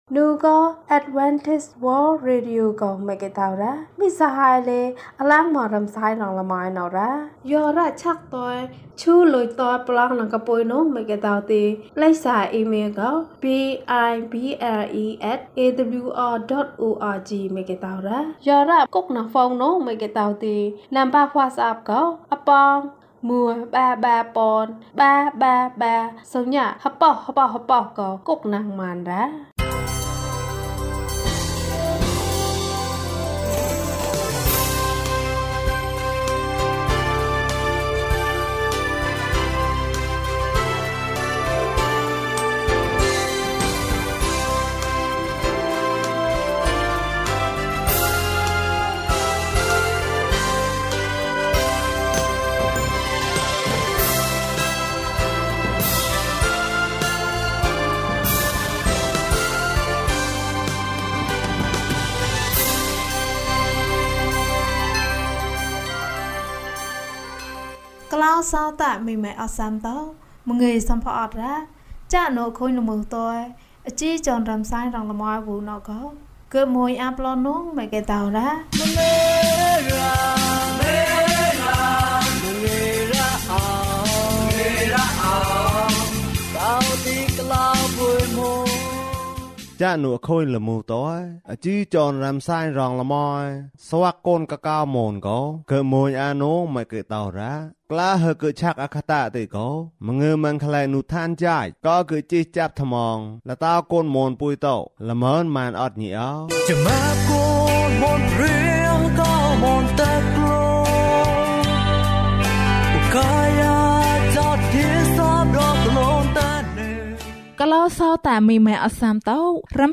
အံ့သြဘွယ်ရာ ကျန်းမာခြင်းအကြောင်းအရာ။ ဓမ္မသီချင်း။ တရားဒေသနာ။